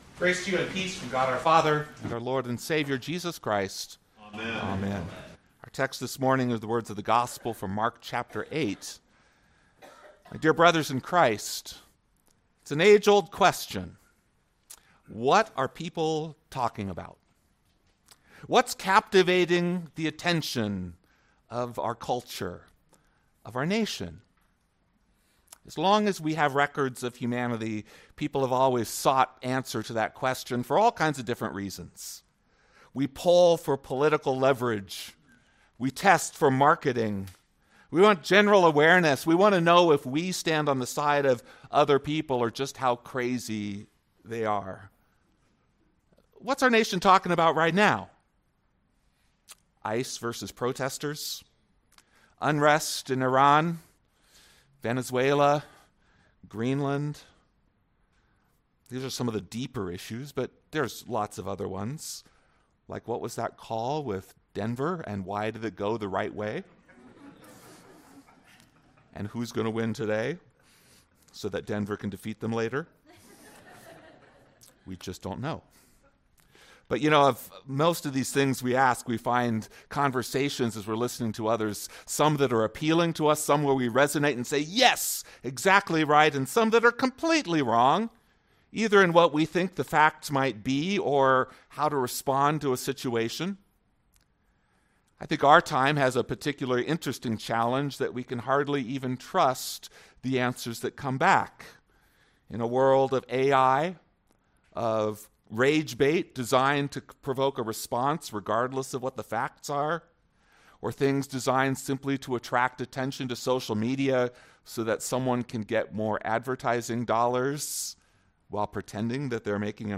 Wednesday Sermon